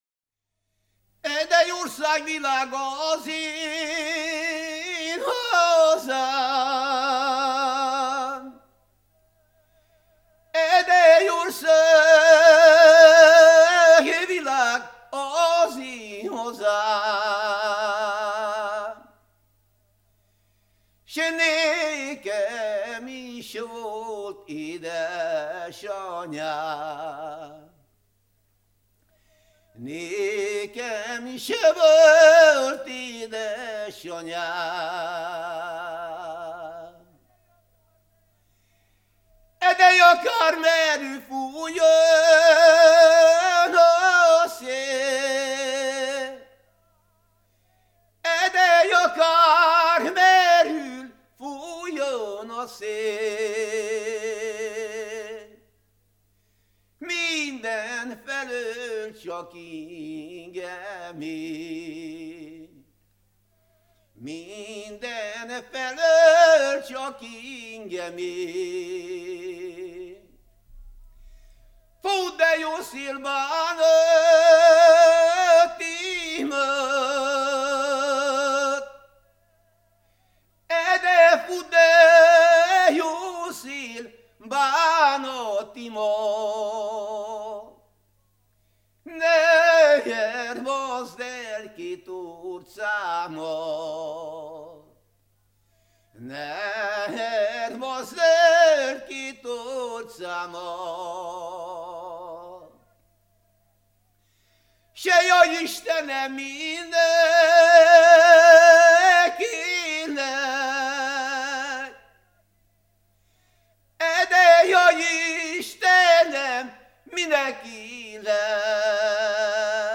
ének
Türe
Nádas mente (Kalotaszeg, Erdély)